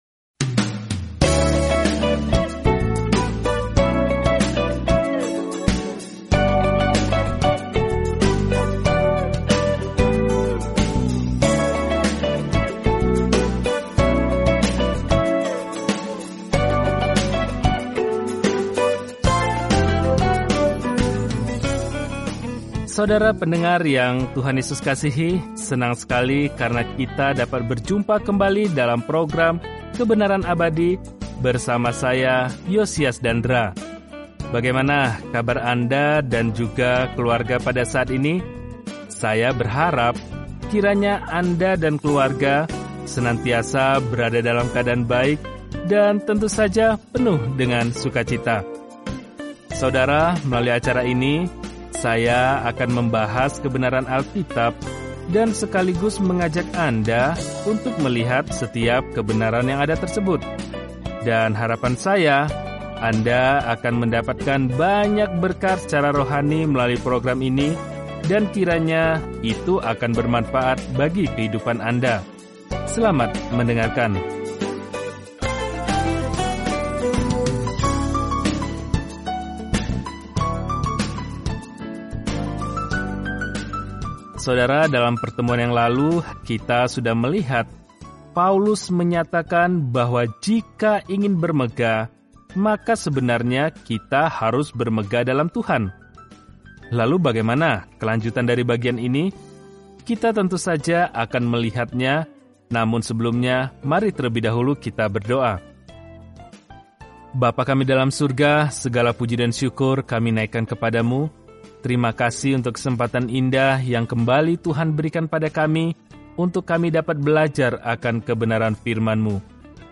Apakah topik tersebut dibahas dalam surat pertama kepada jemaat di Korintus, memberikan perhatian praktis dan koreksi terhadap permasalahan yang dihadapi kaum muda Kristen. Telusuri 1 Korintus setiap hari sambil mendengarkan pelajaran audio dan membaca ayat-ayat tertentu dari firman Tuhan.